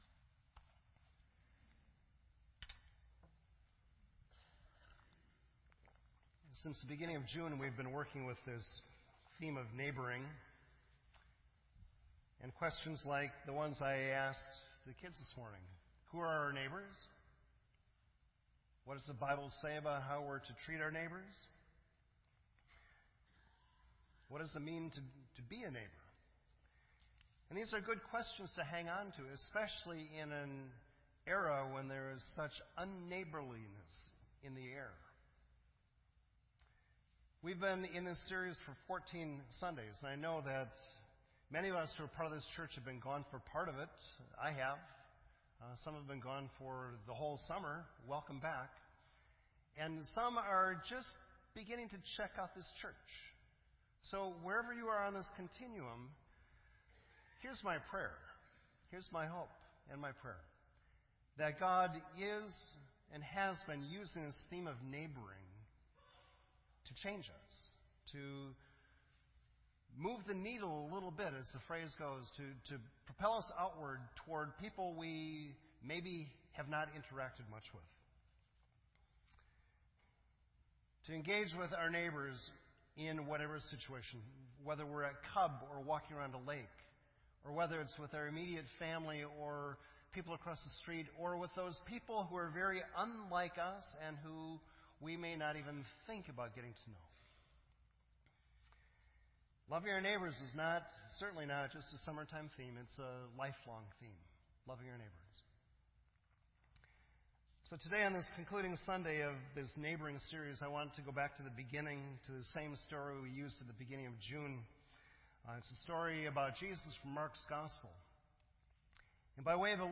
This entry was posted in Sermon Audio on September 10